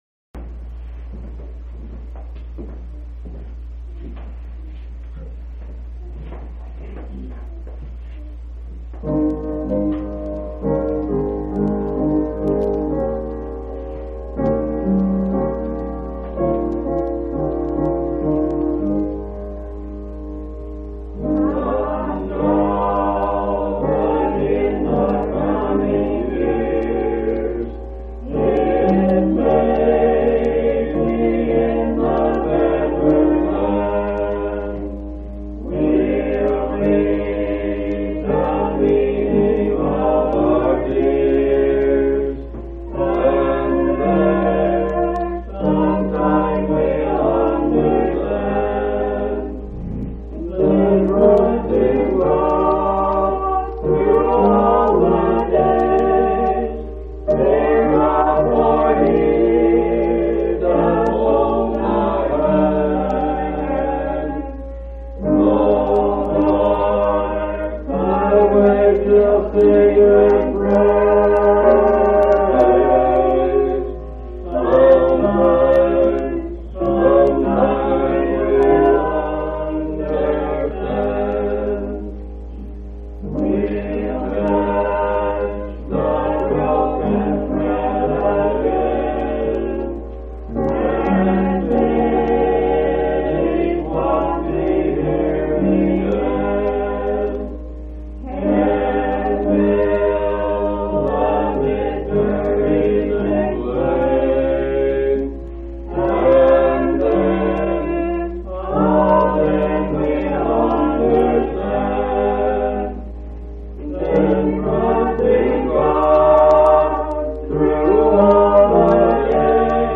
1/1/1967 Location: Grand Junction Local Event